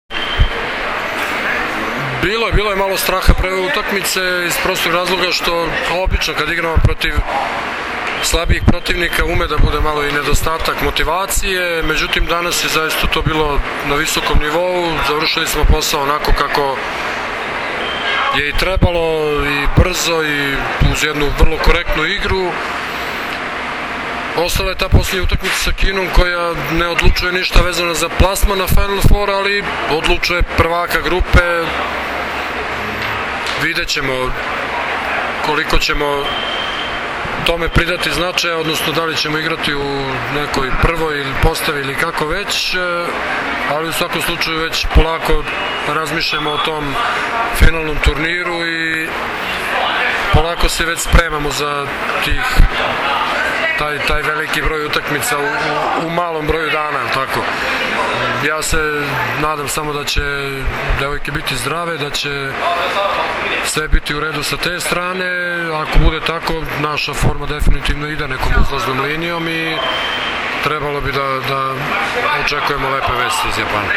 IZJAVA ZORANA TERZIĆA, SELEKTORA SRBIJE